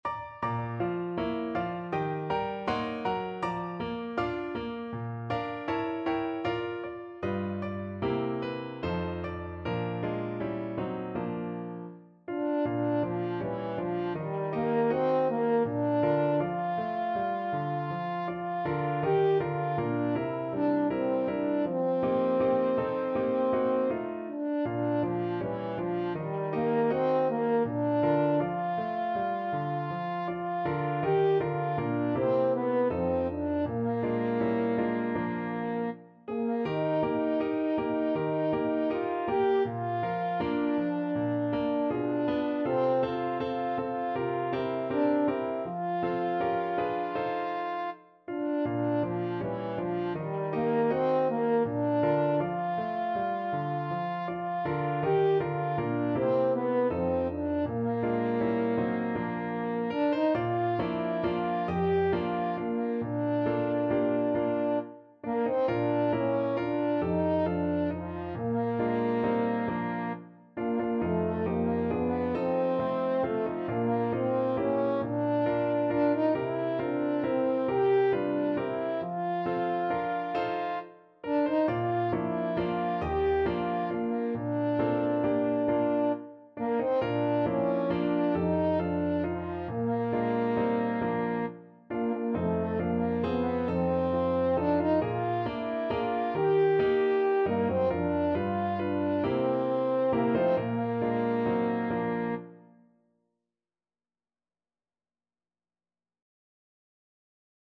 Free Sheet music for French Horn
French Horn
Bb major (Sounding Pitch) F major (French Horn in F) (View more Bb major Music for French Horn )
4/4 (View more 4/4 Music)
Andante